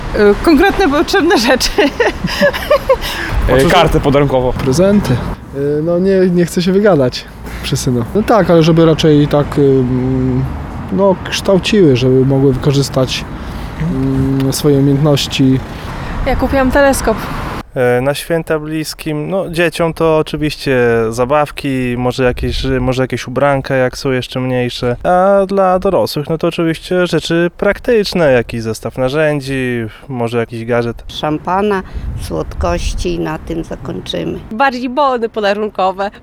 Postanowiliśmy zapytać przechodniów w Suwałkach, co kupili bliskim na święta.